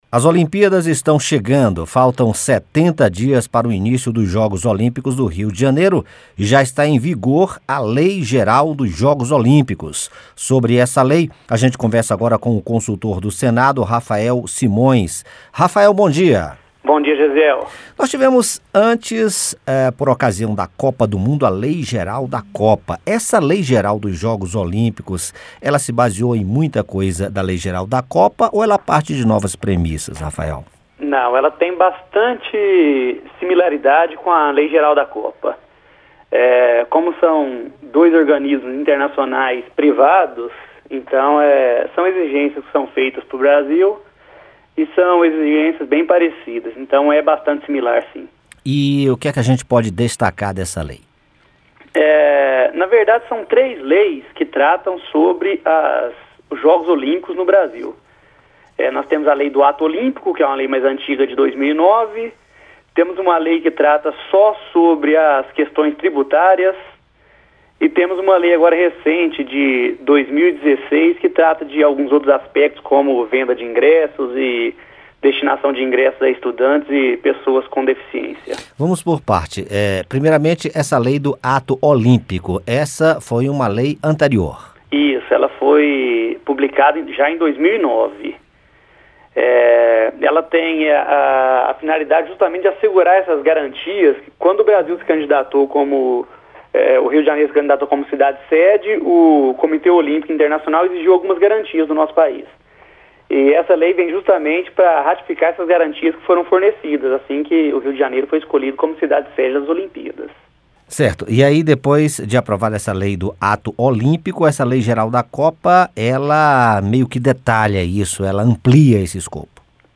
Consultor explica como funciona a Lei Geral das Olimpíadas